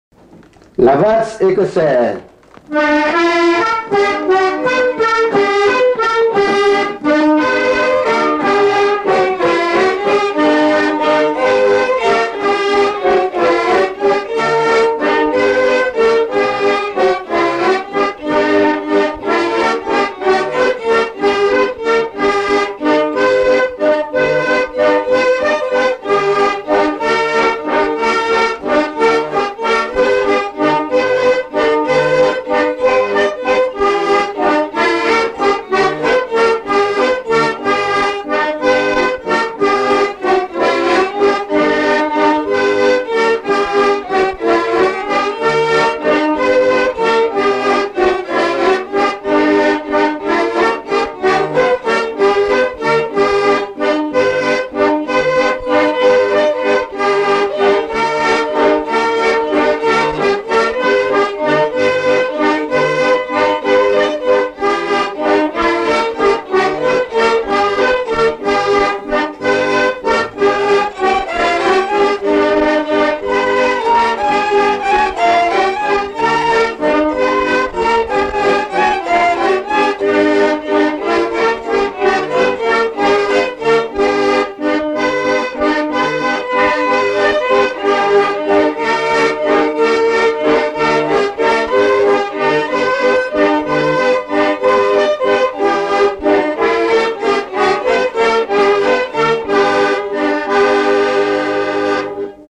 Mémoires et Patrimoines vivants - RaddO est une base de données d'archives iconographiques et sonores.
danse : valse écossaise
Pièce musicale inédite